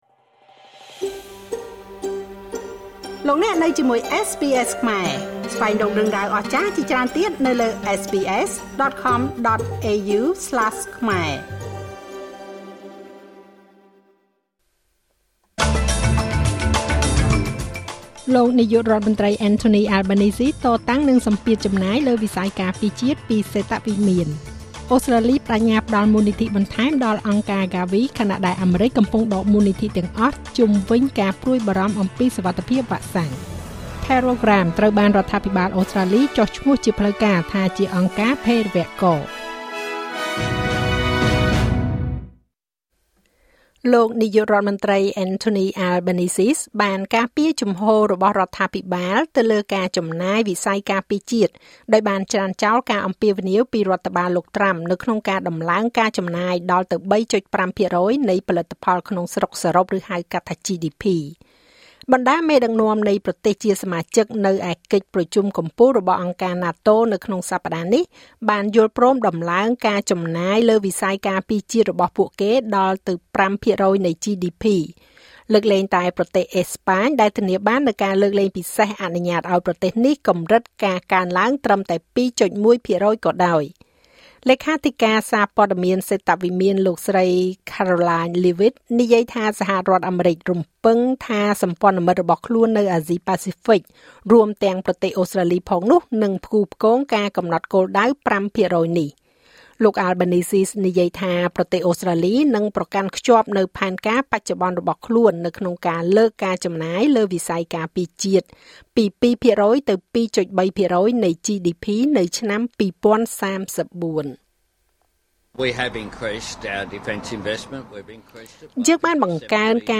នាទីព័ត៌មានរបស់SBSខ្មែរ សម្រាប់ថ្ងៃសុក្រ ទី២៧ ខែមិថុនា ឆ្នាំ២០២៥